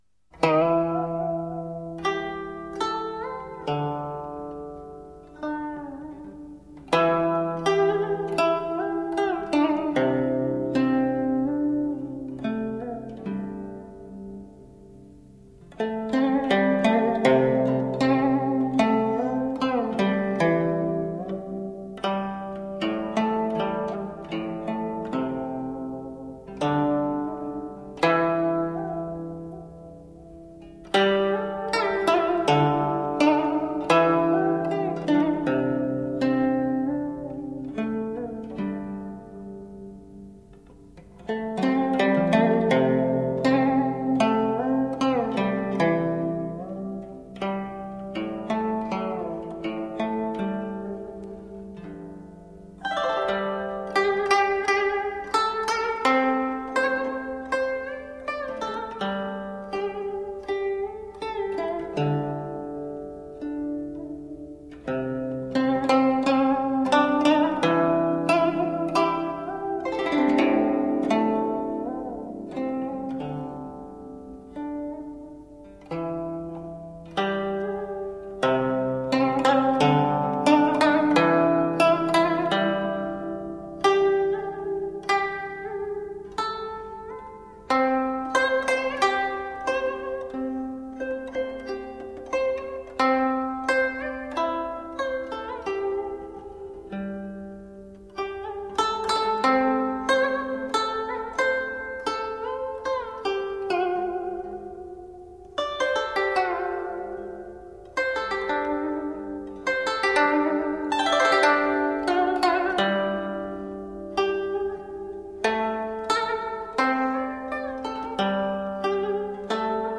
各种弹拨乐器的声音清晰透亮，余韵丰富，充满强烈的质感和牵引力。“
筝独奏